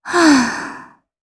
Xerah-Vox_Sigh_jp.wav